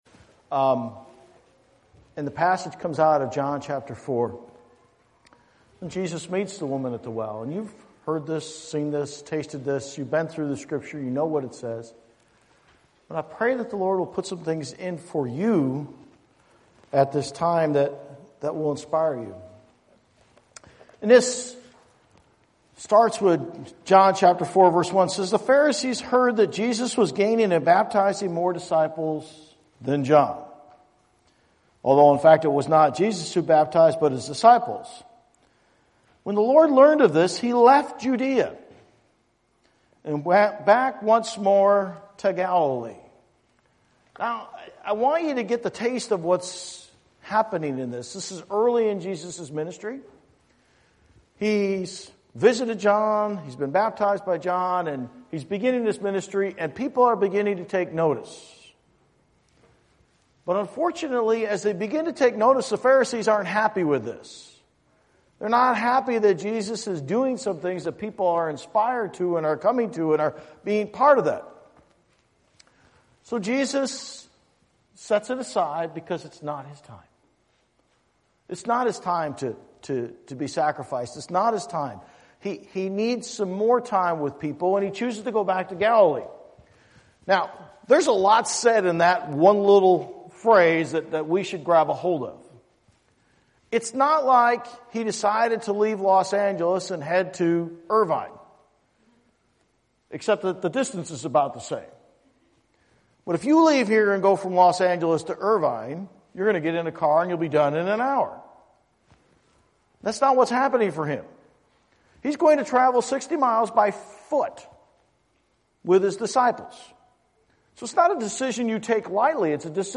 Bible Text: John 4 | Preacher